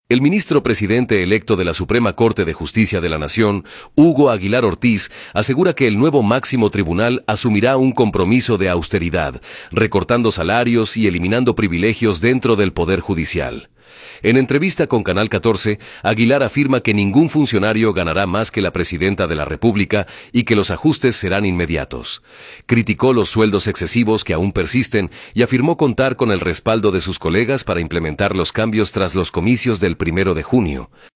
El ministro presidente electo de la Suprema Corte de Justicia de la Nación, Hugo Aguilar Ortiz, asegura que el nuevo máximo tribunal asumirá un compromiso de austeridad, recortando salarios y eliminando privilegios dentro del Poder Judicial. En entrevista con Canal Catorce, Aguilar afirma que ningún funcionario ganará más que la presidenta de la República y que los ajustes serán inmediatos. Criticó los sueldos excesivos que aún persisten y afirmó contar con el respaldo de sus colegas para implementar los cambios tras los comicios del primero de junio.